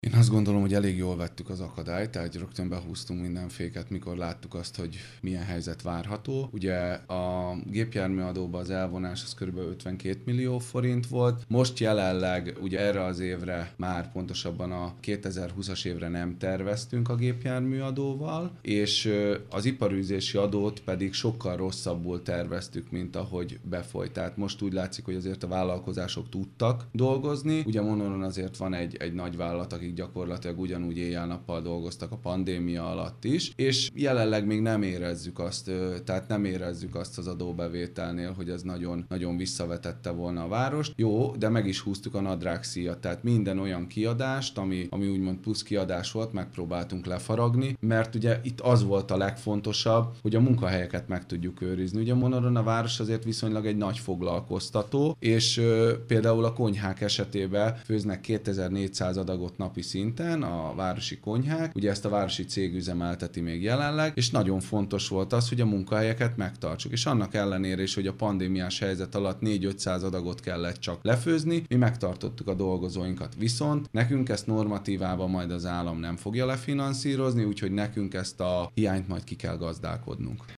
Az így keletkezett hiányt nem finanszírozza az állam, ezért ezt ki kell gazdálkodni. Darázsi Kálmán polgármestert hallják.